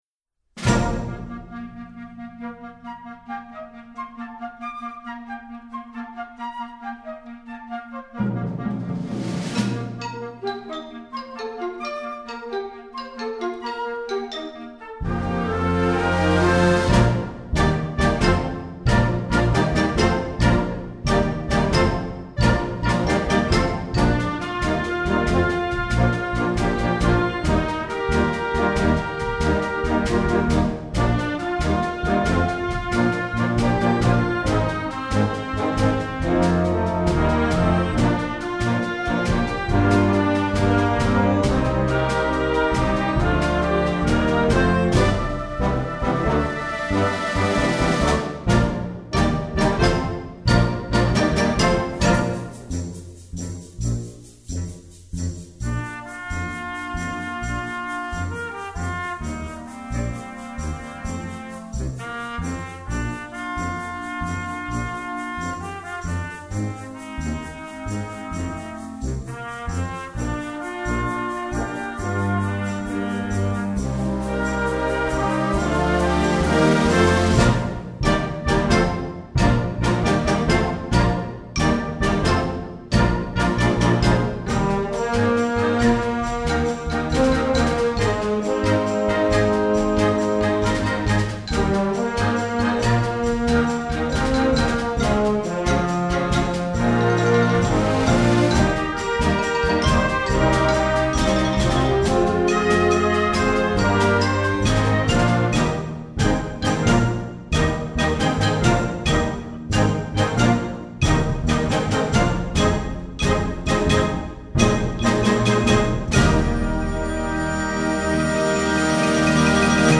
Gattung: Jugendwerk
Besetzung: Blasorchester